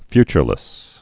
(fychər-lĭs)